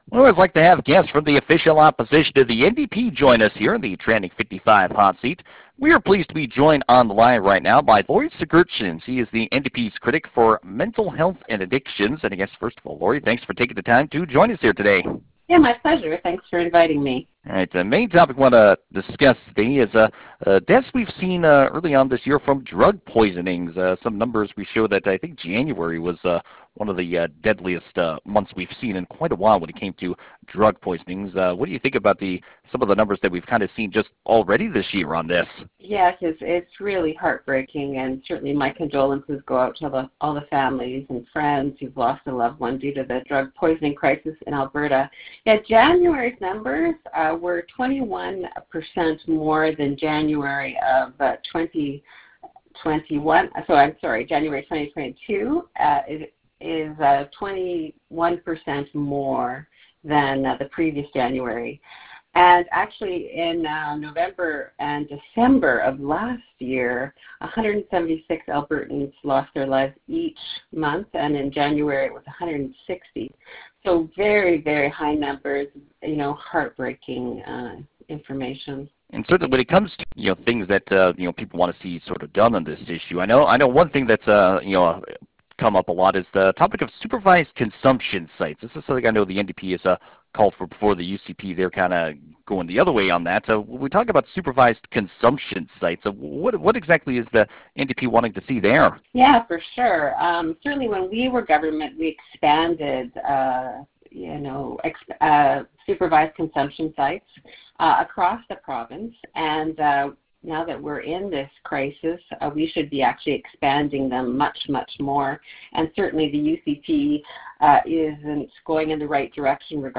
We talked to Sigurdson on the Trending 55 Hot Seat, you can hear that full discussion below.